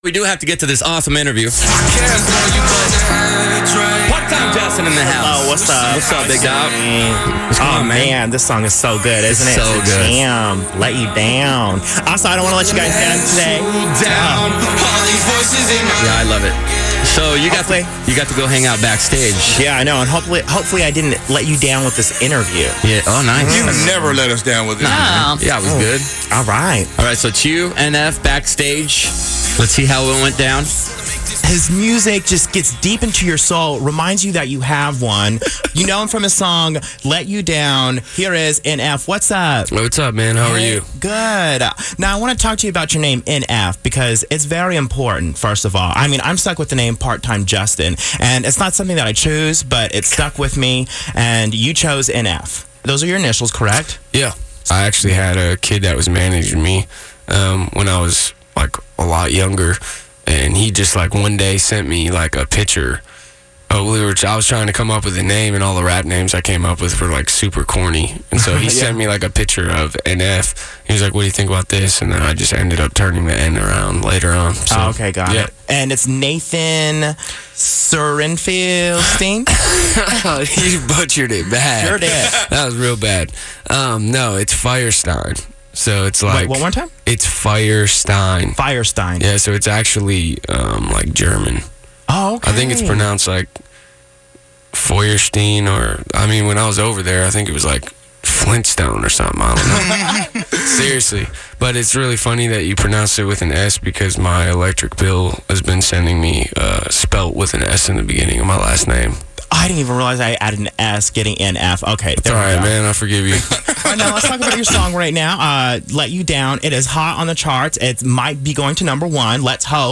KiddNation Celebrity Interviews